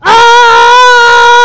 Crits » Girl Scream
描述：Girl Scream
标签： 666moviescreams Scream Screaming Girl scary terror
声道立体声